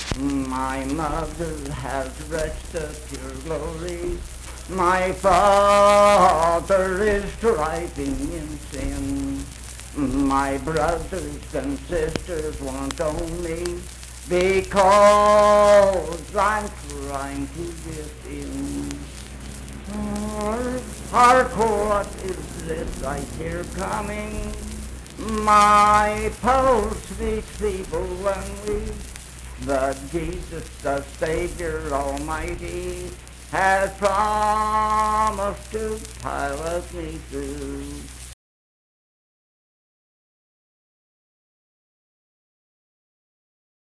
Unaccompanied vocal music
Hymns and Spiritual Music
Voice (sung)